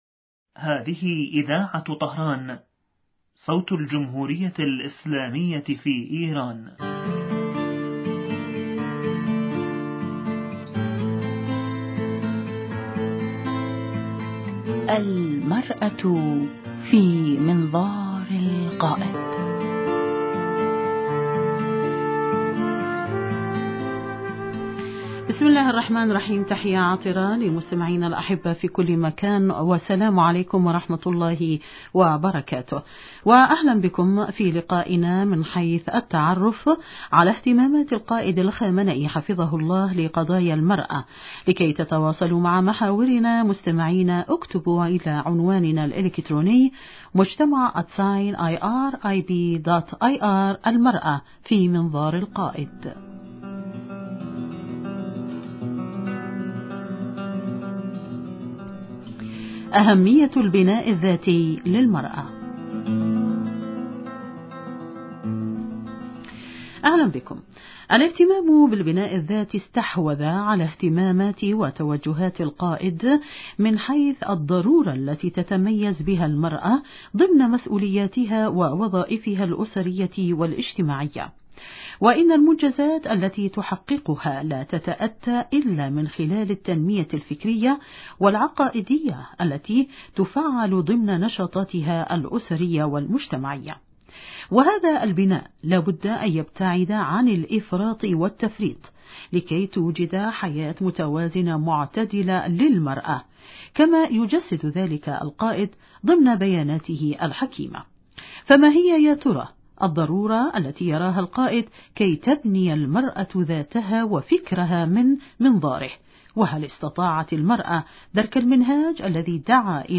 ضيف البرنامج عبر الهاتف